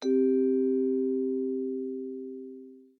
Gentle_gong.ogg